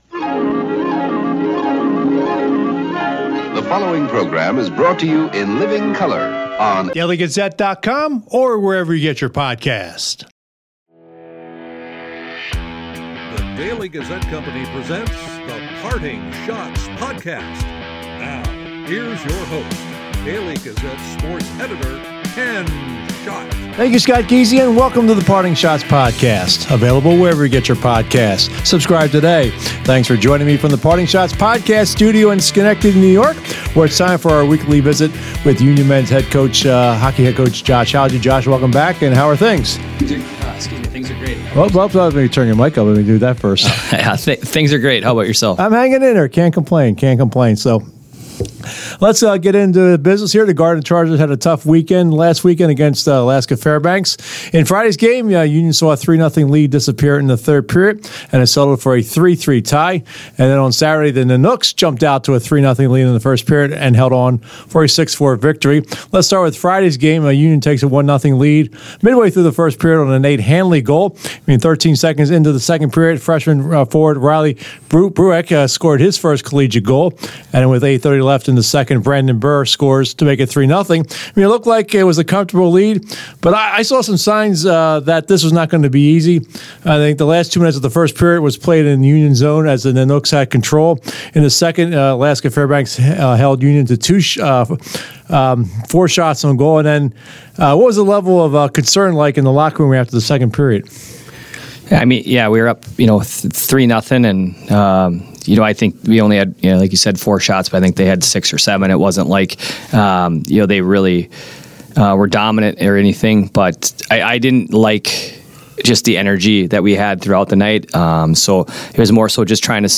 They will also answer questions from the listeners.